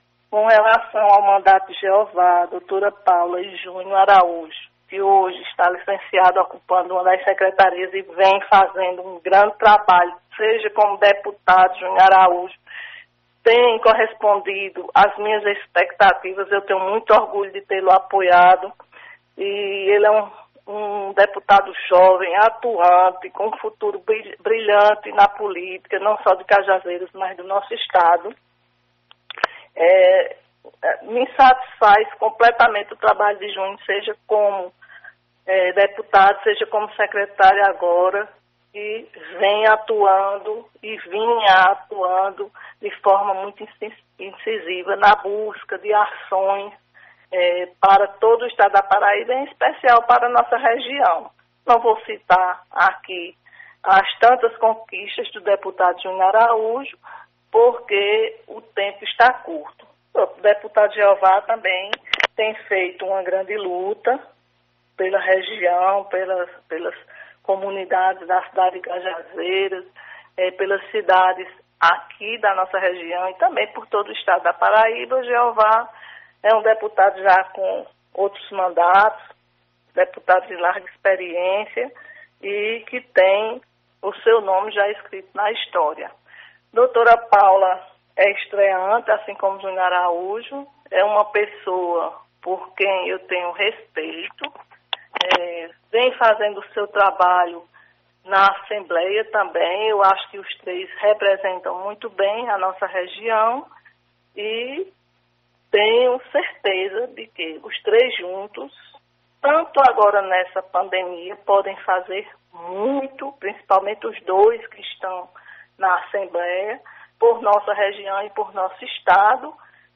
A ex – prefeita de Cajazeiras, atual secretária executiva de Desenvolvimento Humano e pré – candidata a prefeita nas eleições de 2020, Dra. Denise Albuquerque ( Cidadania), foi a entrevistada do programa Rádio Vivo da Alto Piranhas desta quinta – feira (21).